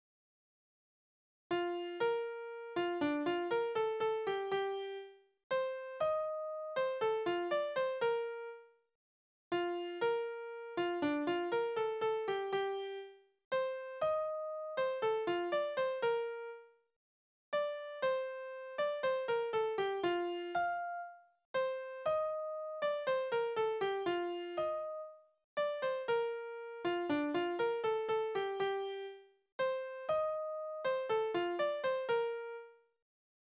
Liebeslieder: Die Liebe zur Schäferin
Tonart: B-Dur
Taktart: 4/4
Tonumfang: kleine Dezime
Besetzung: vokal